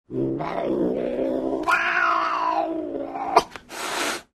Злобное мяуканье разгневанного кота